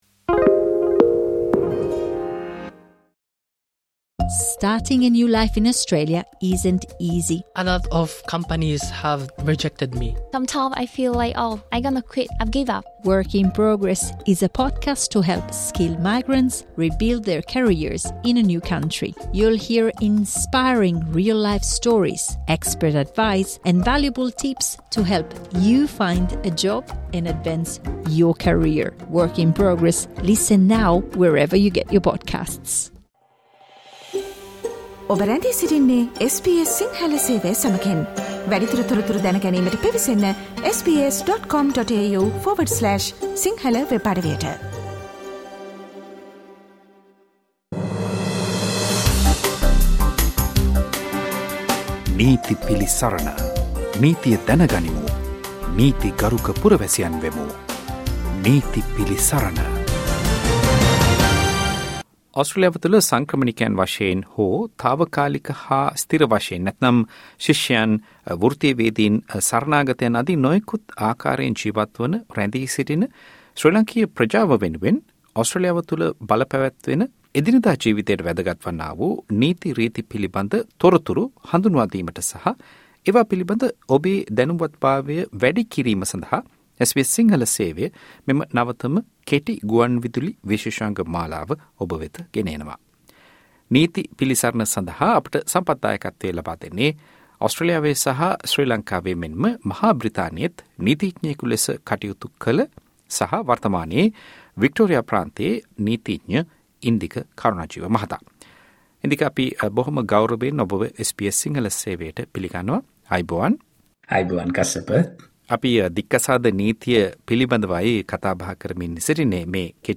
ඕස්ට්‍රේලියාවේ ජීවත් වන ඔබට වැදගත් වන නීති කරුණු පැහැදිලි කරන නීති පිළිසරණ විශේෂාංගය. මෙම වැඩසටහන දික්කසාද නීතිය පිළිබඳ සාකච්ඡාවේ තෙවැනි කොටස.